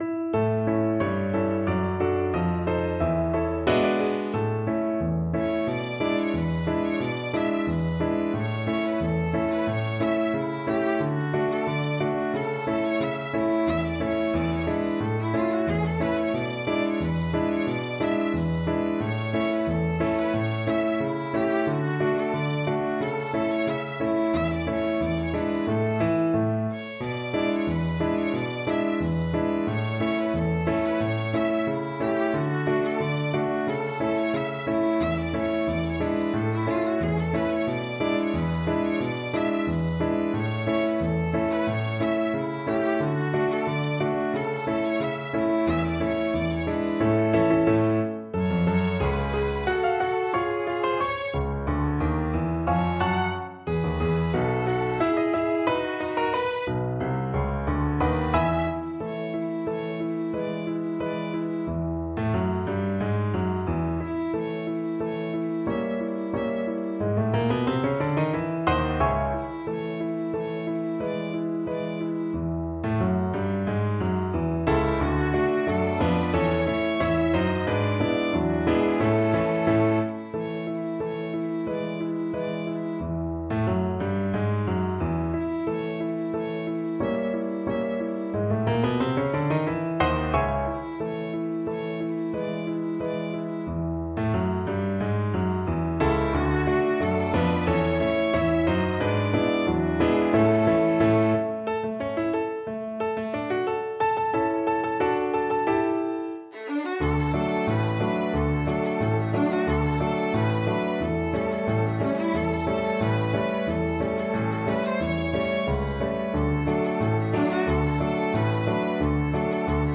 Jazz Scott Joplin Combination March Violin version
Violin
A major (Sounding Pitch) (View more A major Music for Violin )
4/4 (View more 4/4 Music)
= 180 Andante
Jazz (View more Jazz Violin Music)